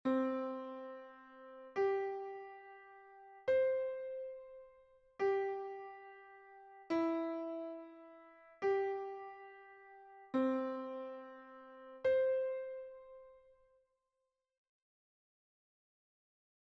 Exercise 3: C, C + E, G.
3_C_C_G_E.mp3